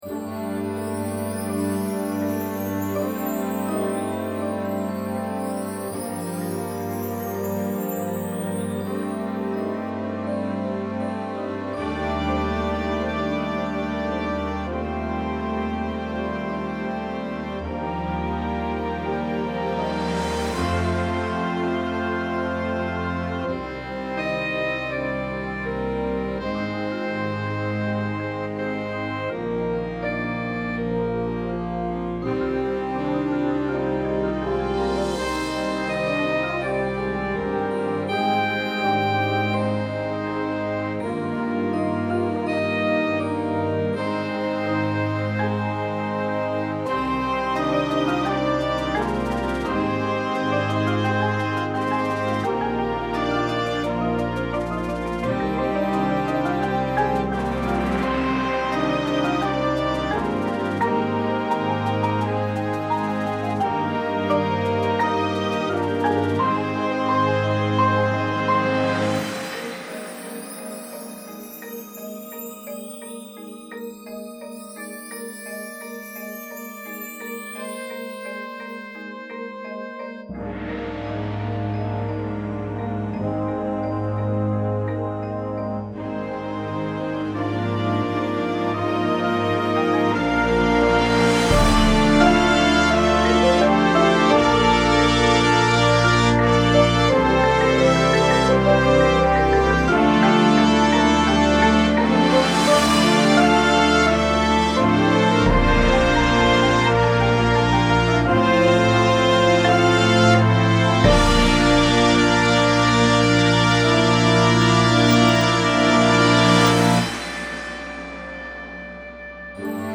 Instrumentation:
• Flute
• Clarinet 1, 2
• Alto Sax 1, 2
• Trumpet 1
• Horn in F
• Trombone 1, 2
• Tuba
• Snare Drum
• Synthesizer
• Marimba – Two parts
• Vibraphone – Two parts
• Glockenspiel